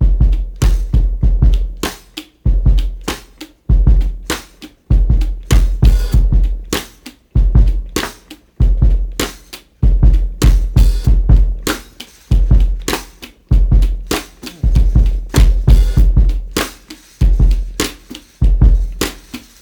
Simp break 2.wav